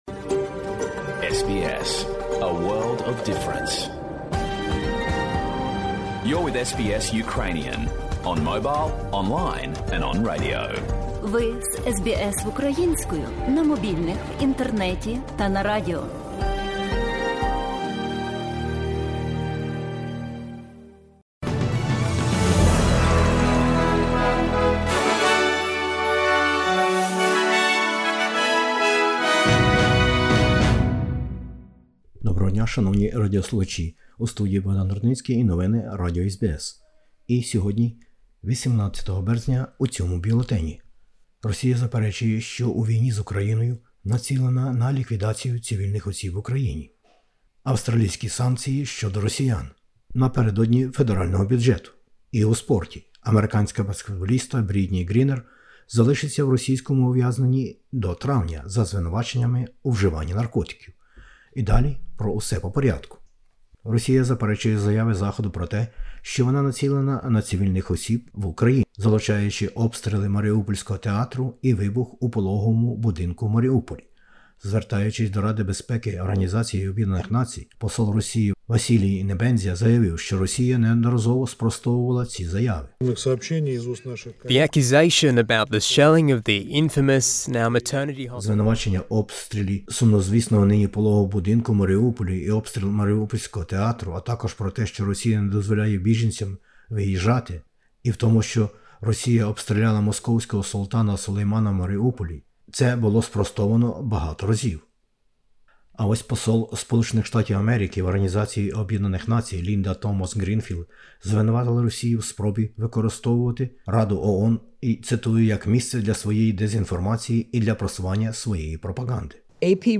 Бюлетень новин SBS українською. ООН про бомбардування російськими збройними силами цивільних людей та цивільних об'єктів.